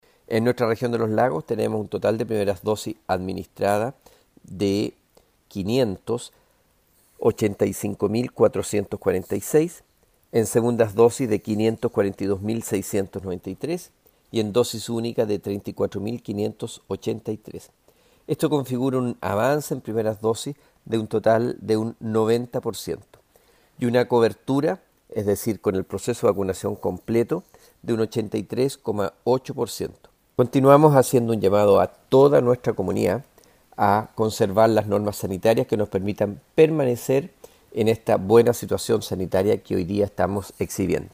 En cuanto al proceso de vacunación, el seremi Alejandro Caroca expresó que continúa en aumento el porcentaje de personas inoculadas.